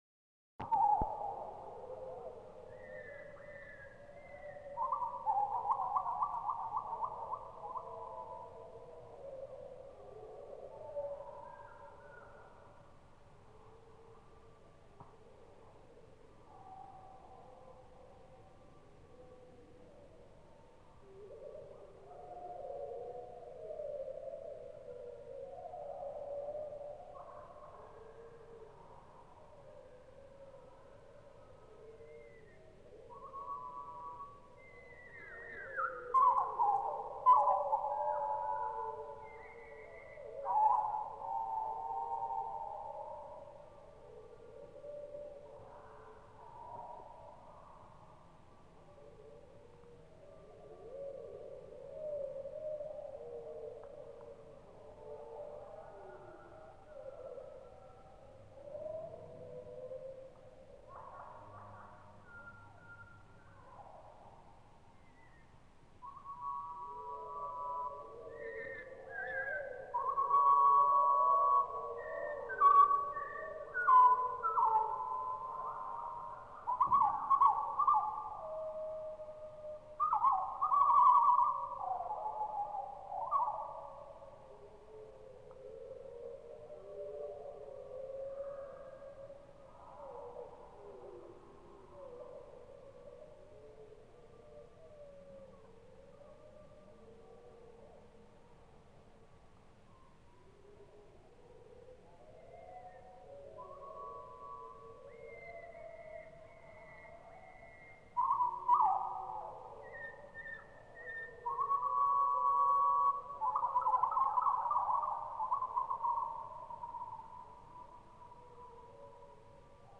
Chants d'oiseaux
Lieu : Gers
Genre : paysage sonore